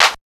AMB147CLAP-L.wav